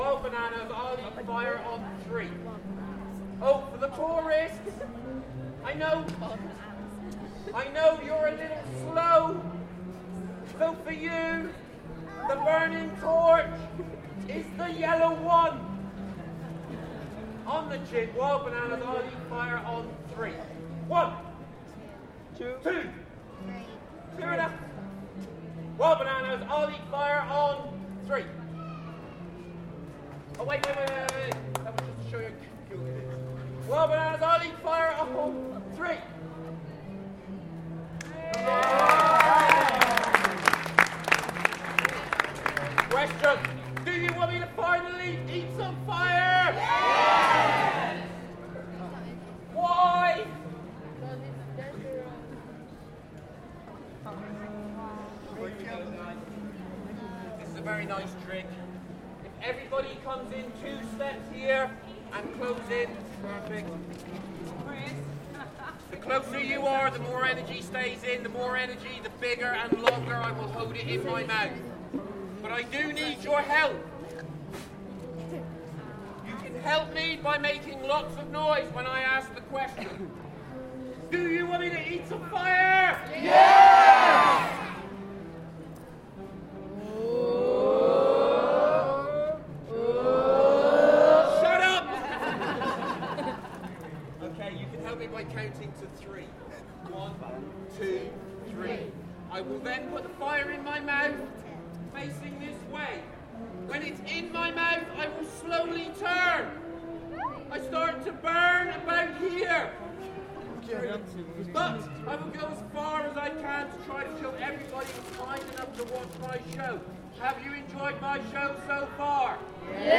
Fire eater outside Bath Abbey
Street performer eating fire in front of a crowd in Bath.